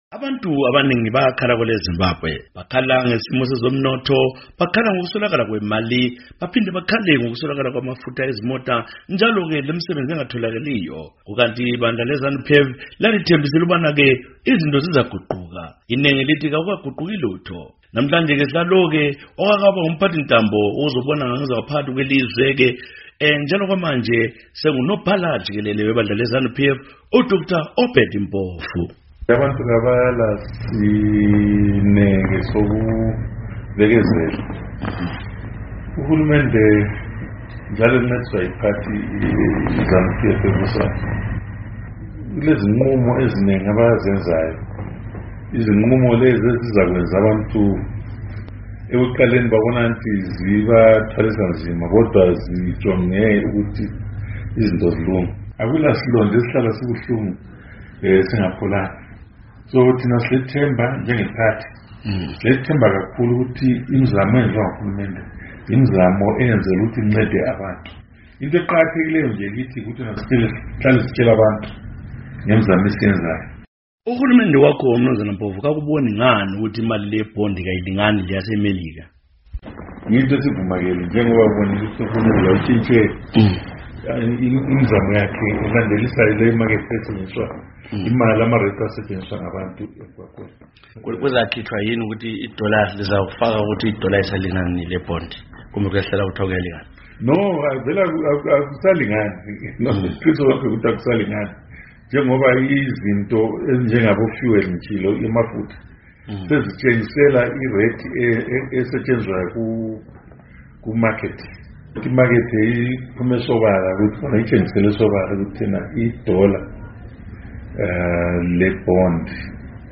Exoxa leStudio 7, uMnu. Mpofu, owake waba ngumphathintambo kahulumende obona ngokwenzakala ekhaya, uthinte eziphathelane lebutho, amapholisa, umnotho welizwe, kanye lebandla lakhe leZANU PF, lona athi lizabusa kuze kubenaphakade..